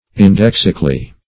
indexically - definition of indexically - synonyms, pronunciation, spelling from Free Dictionary
indexically - definition of indexically - synonyms, pronunciation, spelling from Free Dictionary Search Result for " indexically" : The Collaborative International Dictionary of English v.0.48: Indexically \In*dex"ic*al*ly\, adv. In the manner of an index.